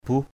/buh/